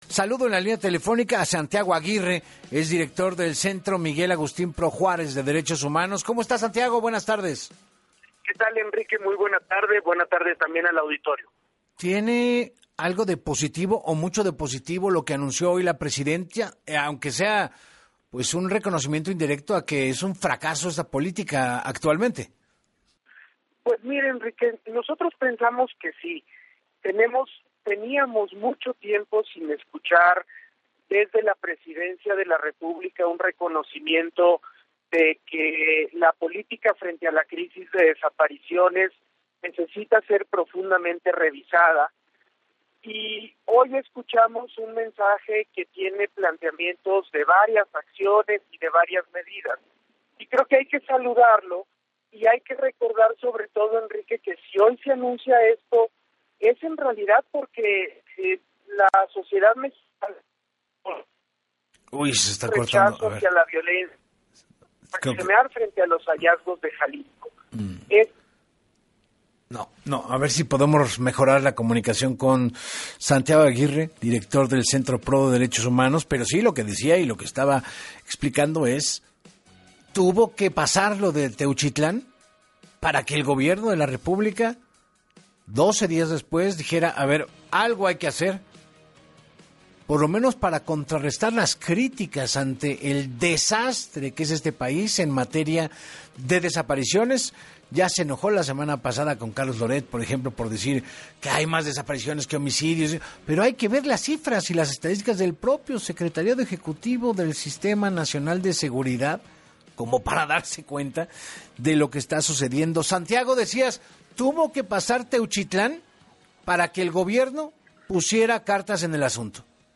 En entrevistas para Así Las Cosas con Enrique Hernández Alcázar, la periodista Marcela Turatti puntualizó que por un lado hay que festejar que por un fin se habla del tema de desapariciones, cuando en el sexenio anterior se evadía.